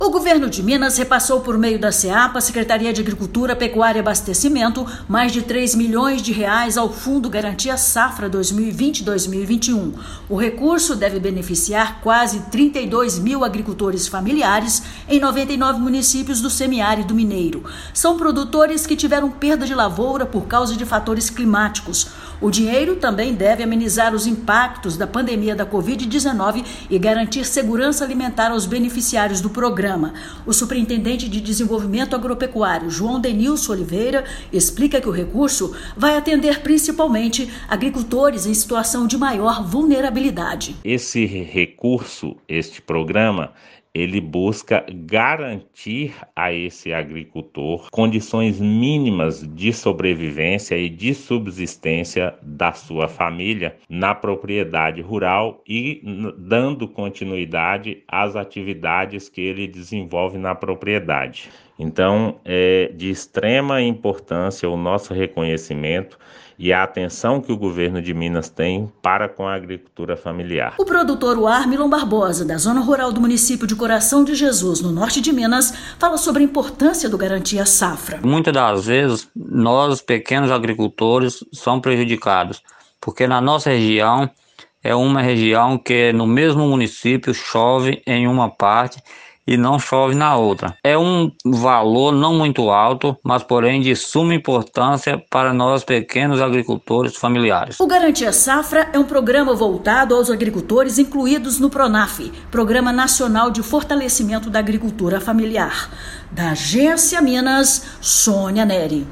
[RÁDIO] Governo de Minas repassa R$3,2 milhões ao Fundo Garantia Safra 2020/2021
Mais de R$ 3 milhões do aporte estadual foram repassados pelo Governo de Minas, por meio da Secretaria de Estado de Agricultura, Pecuária e Abastecimento (Seapa), ao Fundo Garantia Safra 2020/2021. Ouça a matéria de rádio.